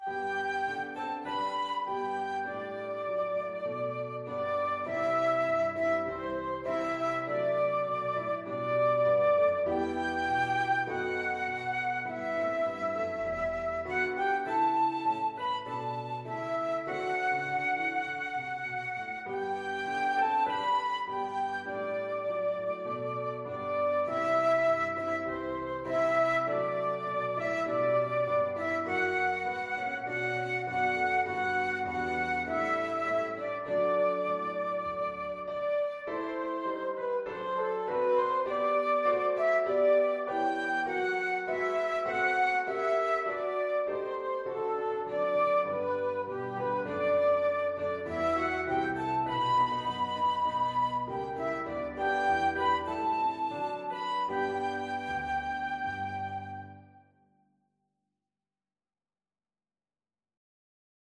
Traditional Trad. Amhran na bhFiann (A Soldier's Song) (Irish National Anthem) Flute version
Flute
4/4 (View more 4/4 Music)
G major (Sounding Pitch) (View more G major Music for Flute )
Traditional (View more Traditional Flute Music)
irish_nat_anth_FL.mp3